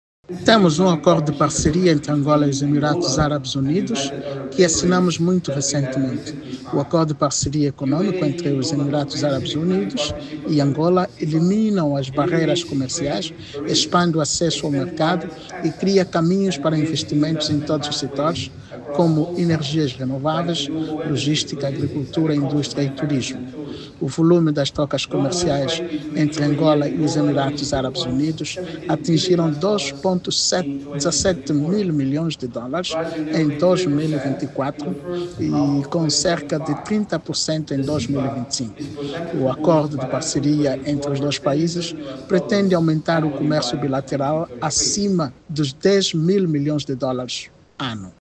A intenção foi expressa pelo Ministro de Estado para a Coordenação Económica, José de Lima Massano, durante a sua intervenção no Fórum de Alto Nível dedicado a investidores, realizado no Dubai, no quadro do The Africa Debate Emirados Árabes Unidos 2025.